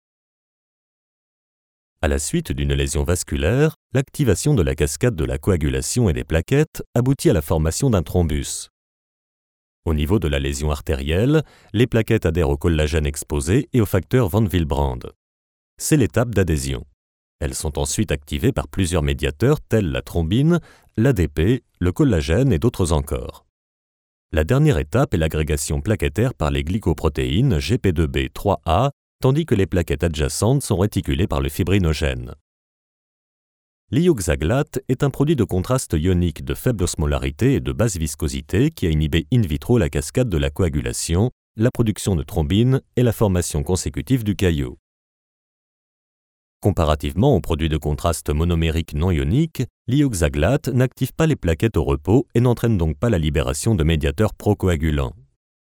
Sprechprobe: Sonstiges (Muttersprache):
A chameleon voice, warm and bass.